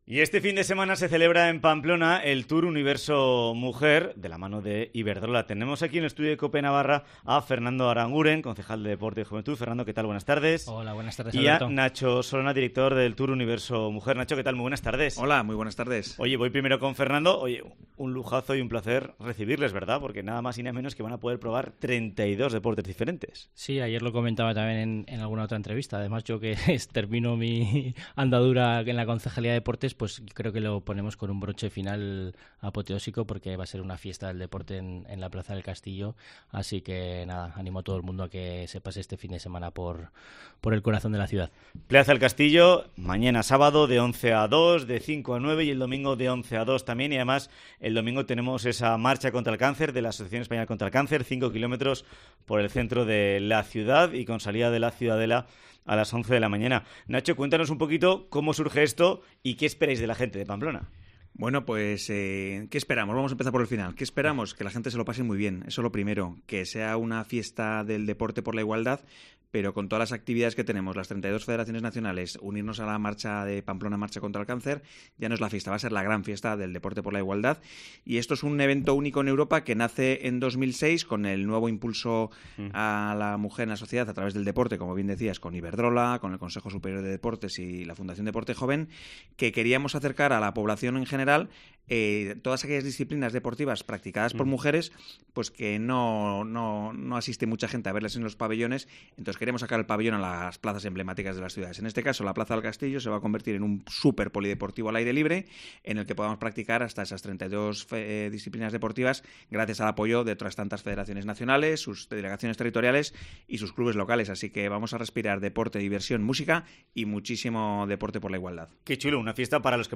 TOUR UNIVERSO MUJER Entrevista